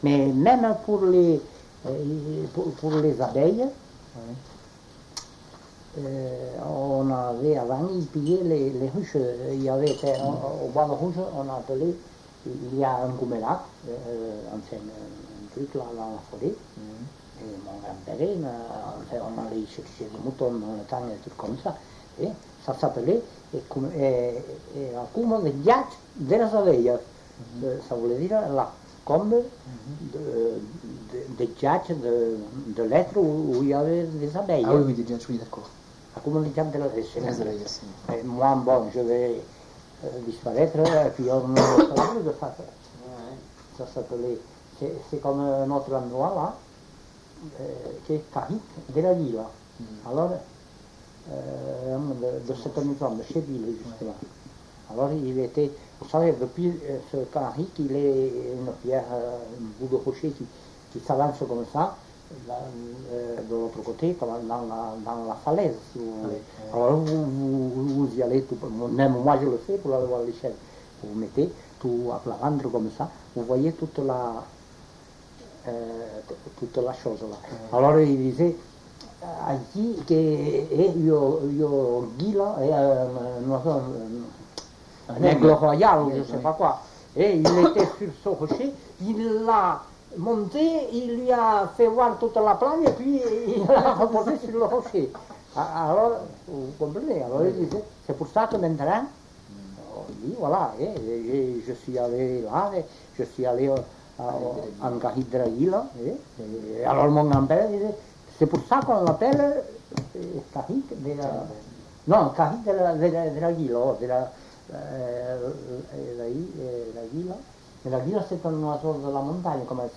Lieu : Eylie (lieu-dit)
Genre : témoignage thématique
Production du son : parlé